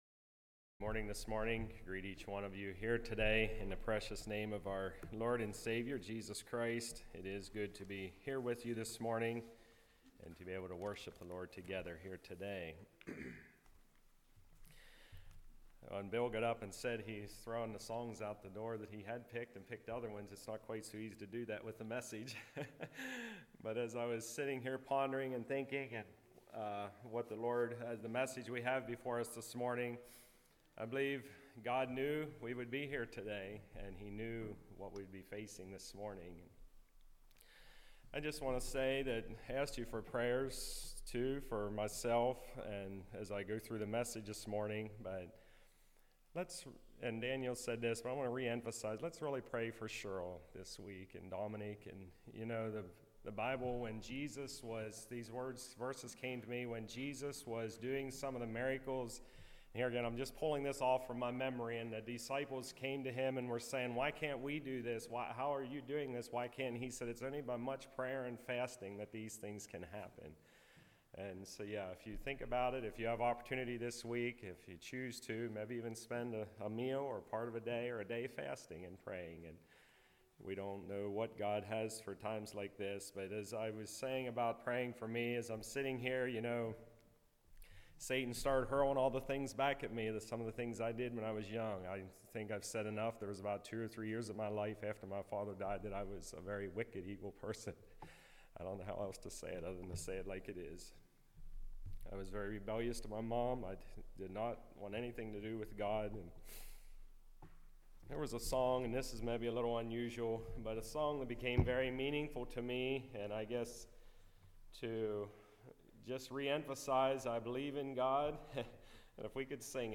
2 Timothy 2:19-26 Service Type: Message Bible Text